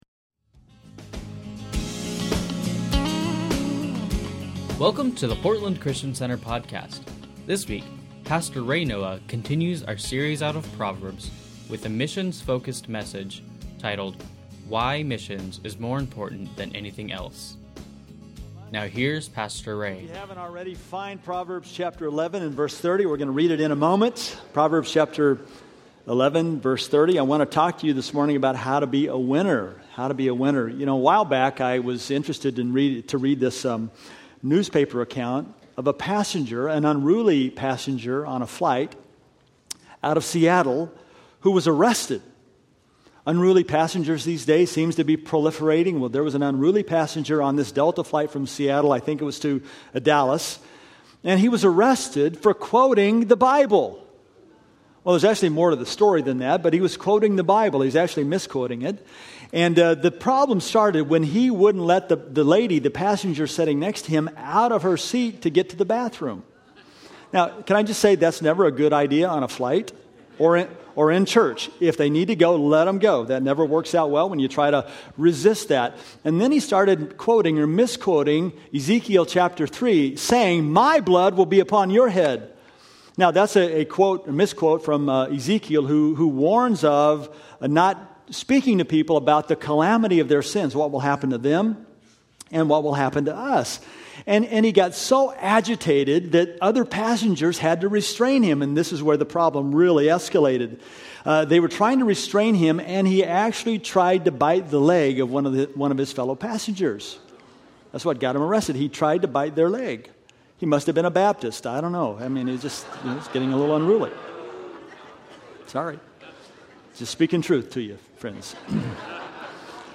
Sunday Messages from Portland Christian Center How to Be A Winner Feb 21 2016 | 00:37:52 1x 00:00 / 00:37:52 Subscribe Share Spotify RSS Feed Share Link Embed